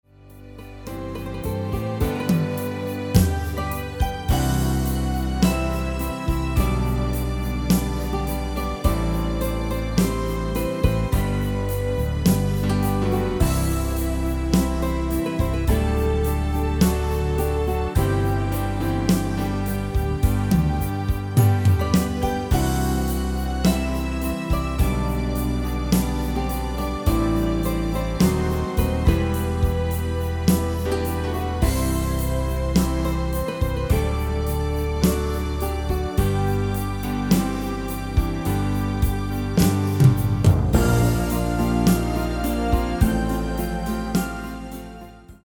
MP3 BackingTrack Euro 4.75
Demo's played are recordings from our digital arrangements.